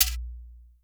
CHEKERE.wav